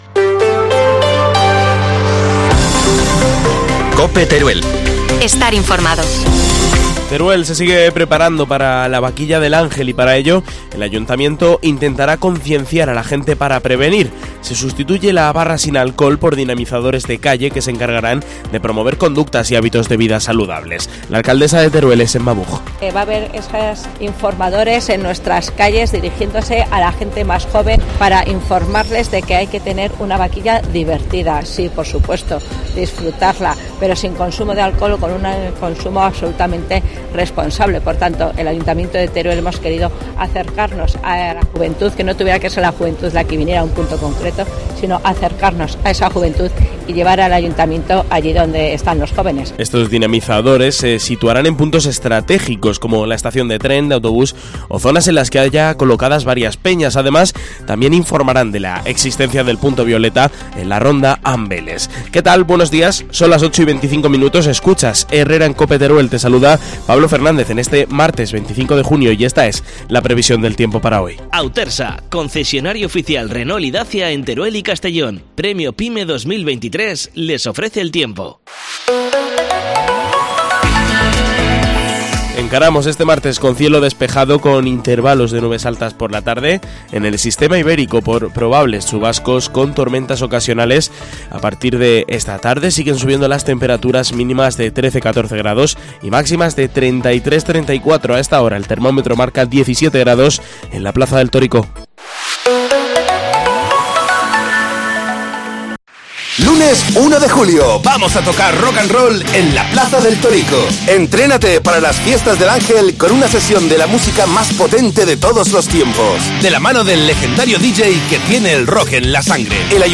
AUDIO: Titulares del día en COPE Teruel